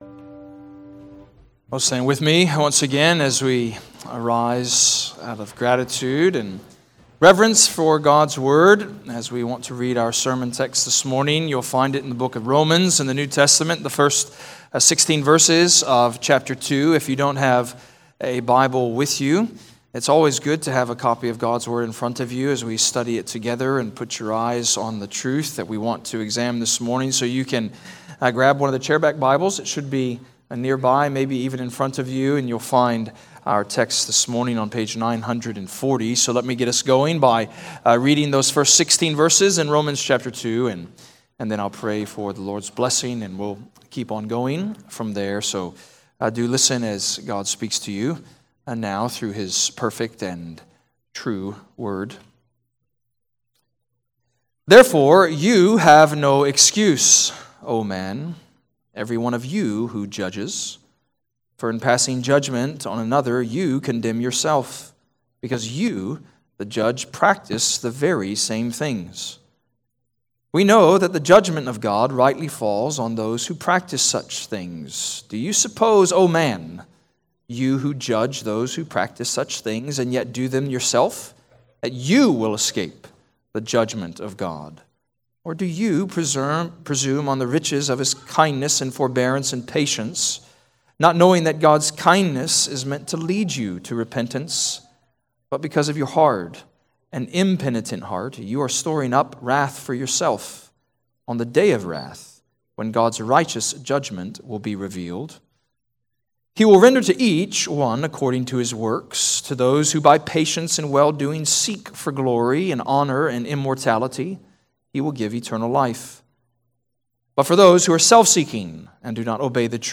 Redeemer Presbyterian Church: Sermon Audio
Download sermons from Redeemer Presbyterian Church in McKinney, TX.